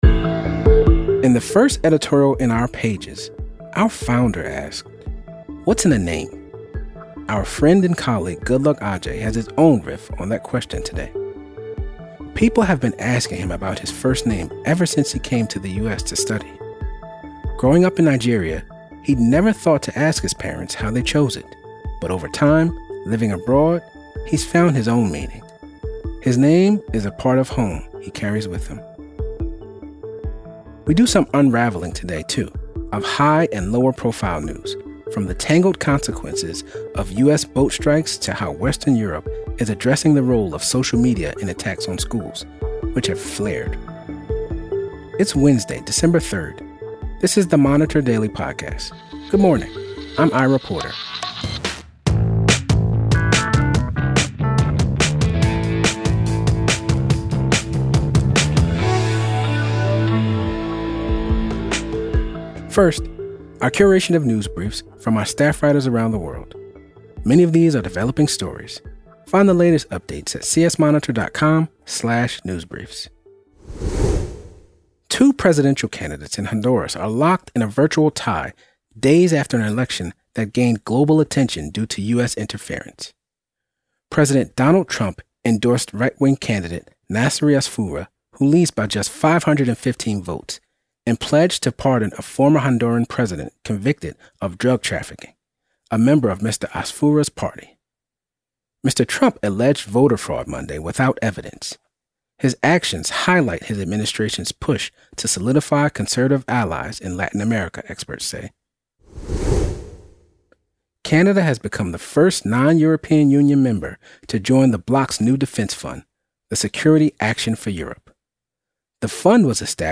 The editors of The Christian Science Monitor take you beyond the headlines with the ideas driving progress in this 15-minute news briefing.